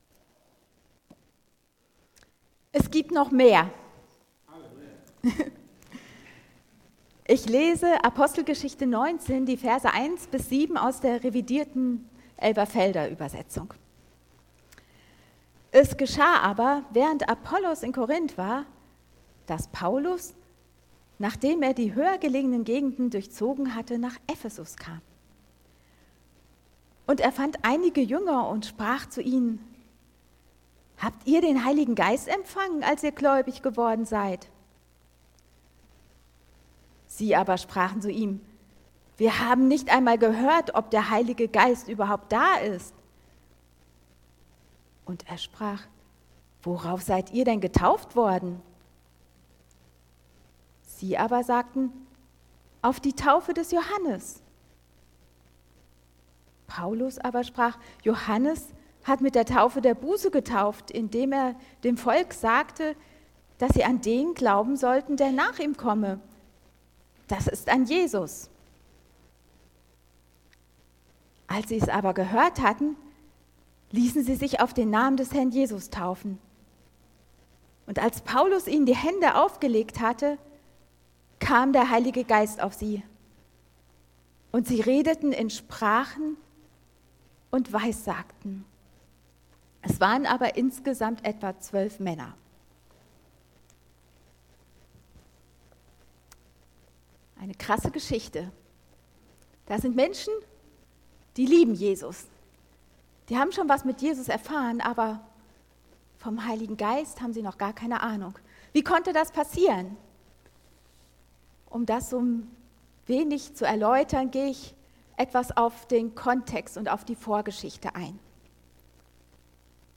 (Gegenwärtig, Teil 4) | Marburger Predigten